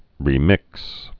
(rē-mĭks)